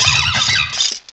pokeemerald / sound / direct_sound_samples / cries / carnivine.aif